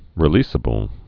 (rĭ-lēsə-bəl)